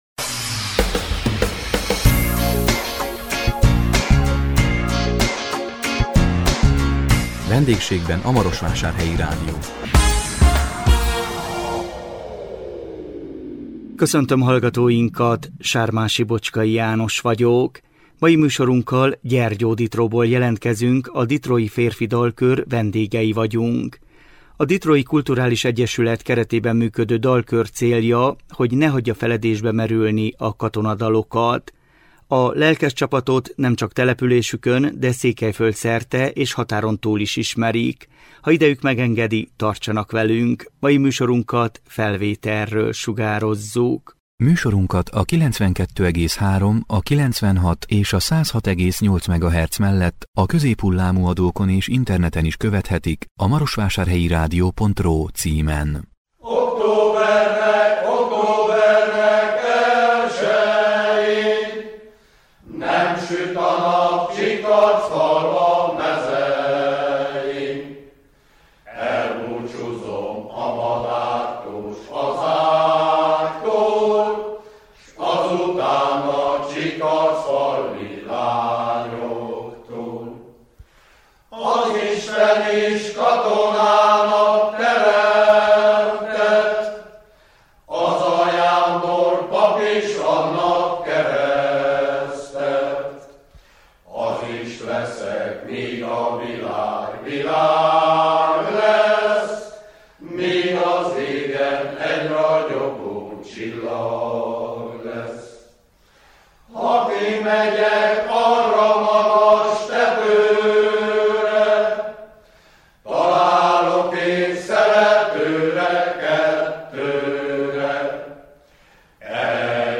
A 2024 november 7-én közvetített VENDÉGSÉGBEN A MAROSVÁSÁRHELYI RÁDIÓ című műsorunkkal Gyergyóditróból jelentkeztünk, a Ditrói Férfi Dalkör vendégei voltunk. A Ditrói Kulturális Egyesület keretében működő dalkör célja, hogy ne hagyja feledésbe merülni a katonadalokat.